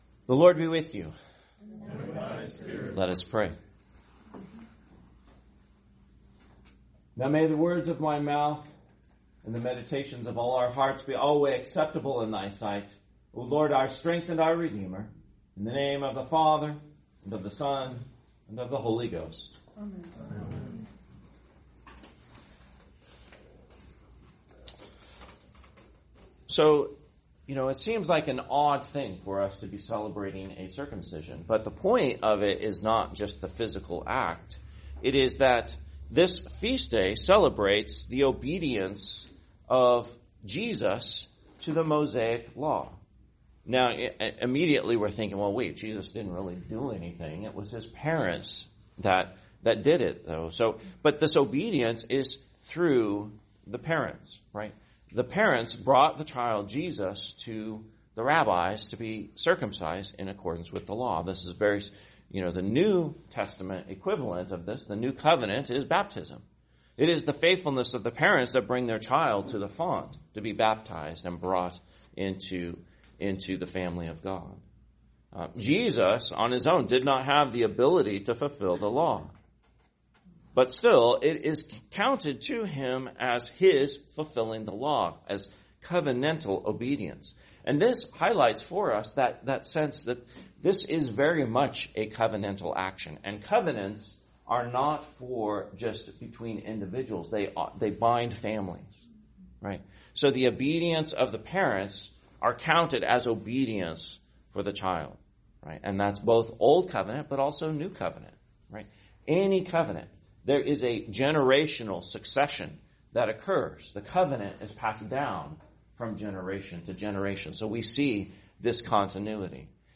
Homily, The Feast of the Circumcision of Christ / The Holy Name, 2026